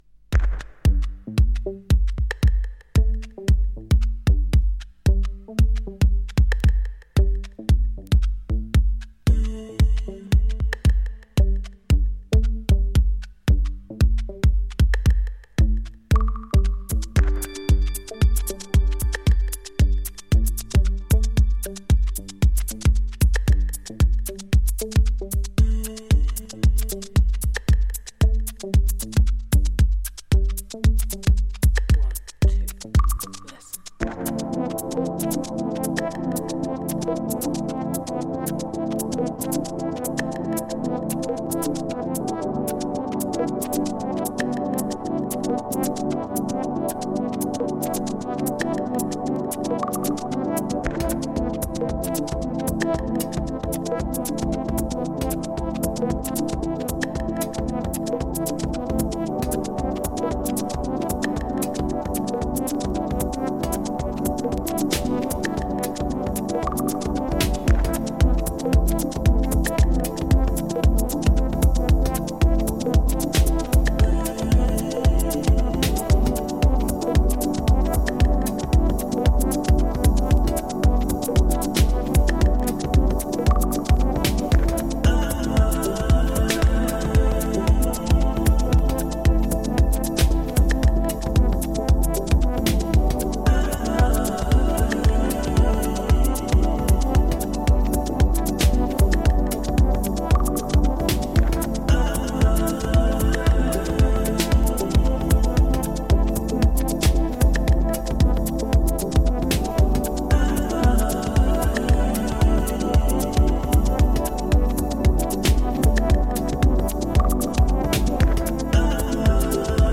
New Release Deep House House